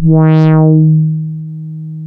MG MOD.D#3 1.wav